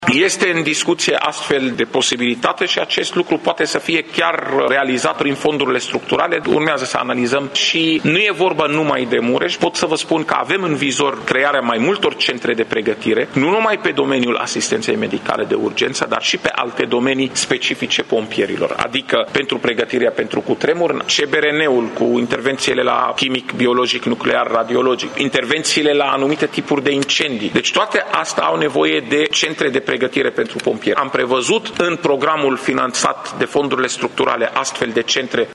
Secretarul de stat în MAI și șeful Departamentului pentru Situații de Urgență, Raed Arafat: